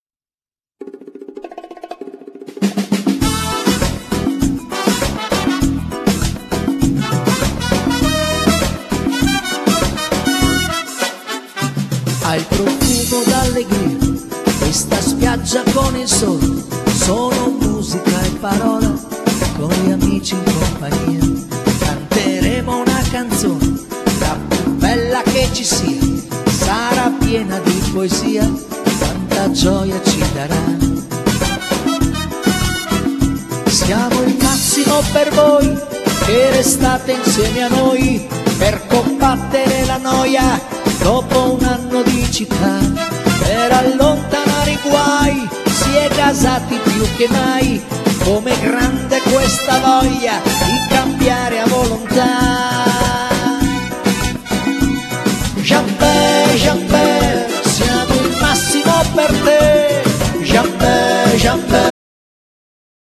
Genere : Liscio folk
meneito